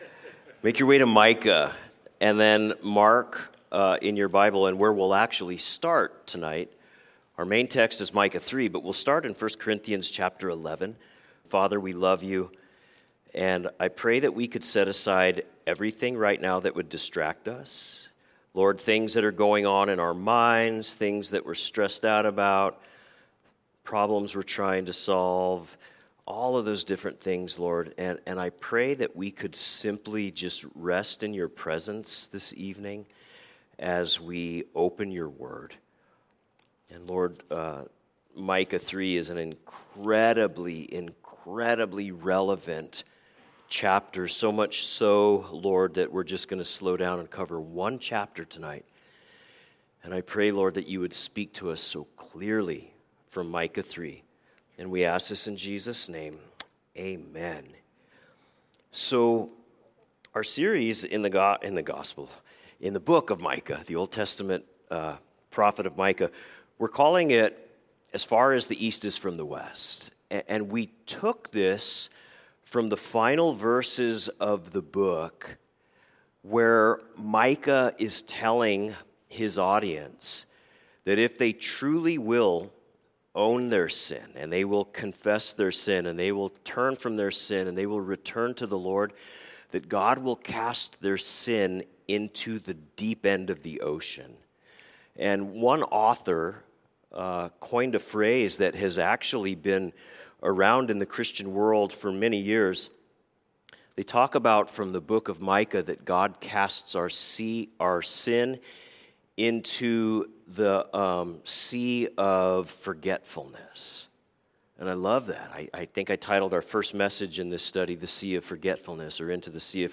A message from the series "Wednesday Evening."